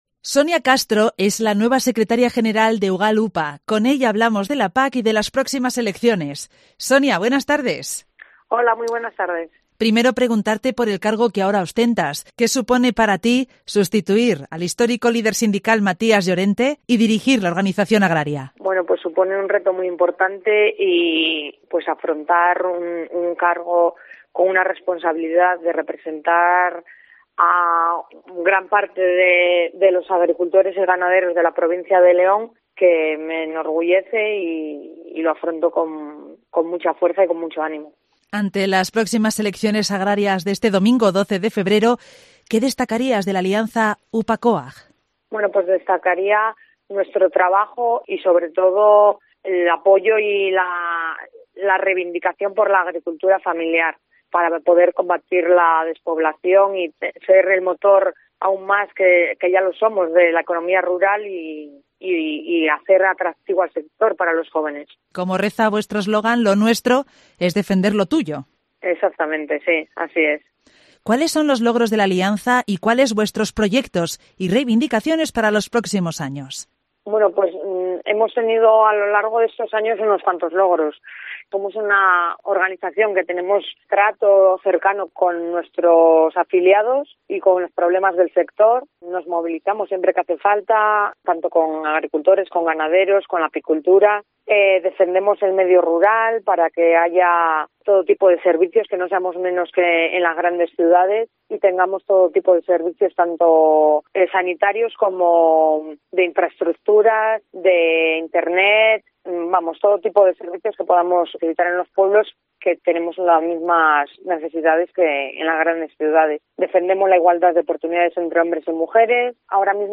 participa hoy jueves en el programa local de Cope León